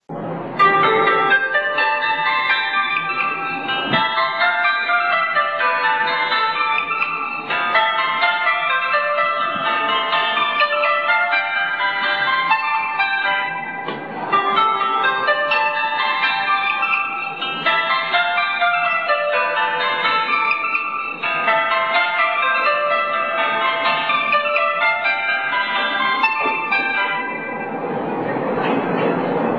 ＪＲ広島駅の列車入線メロディーです。
・２、７番線列車発車放送